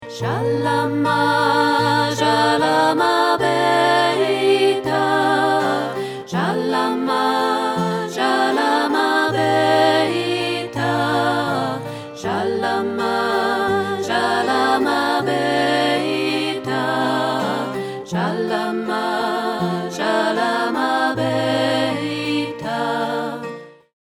trad. Aramäisch